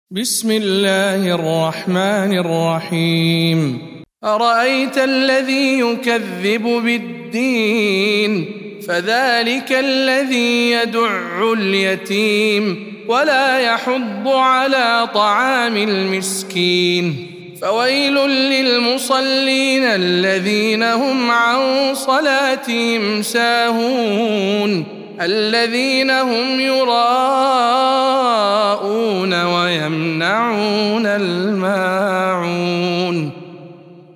سورة الماعون - رواية هشام عن ابن عامر